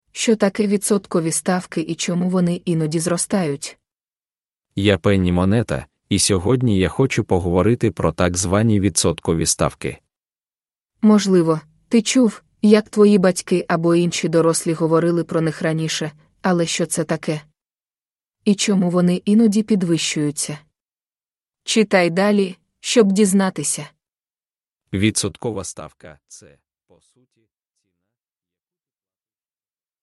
An AI narrated short financial story for children explaining what interest rates are.